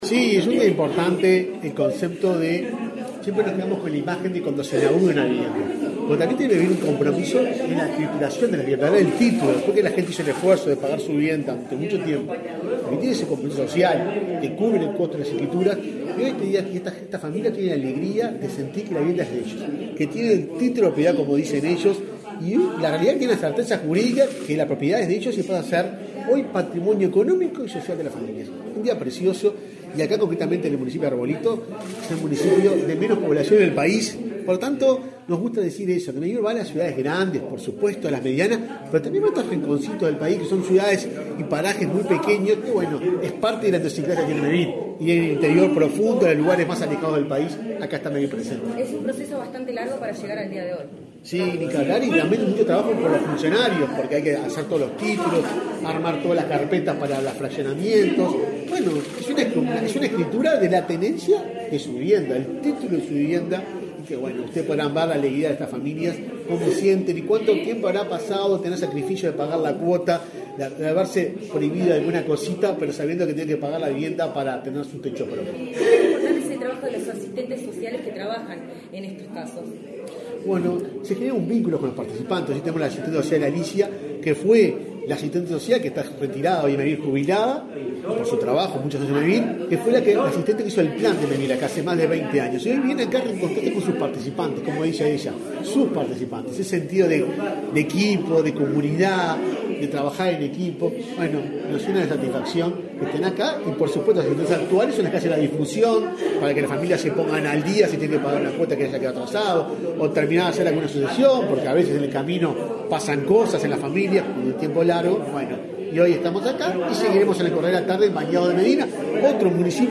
Declaraciones a la prensa del presidente de Mevir, Juan Pablo Delgado
Declaraciones a la prensa del presidente de Mevir, Juan Pablo Delgado 26/07/2022 Compartir Facebook X Copiar enlace WhatsApp LinkedIn Vecinos de las localidades de Bañado de Medina, Arbolito e Isidoro Noblía, en el departamento de Cerro Largo, escriturarán sus viviendas este 26 de julio. El presidente de Mevir, quién participó en el evento, efectuó declaraciones a la prensa.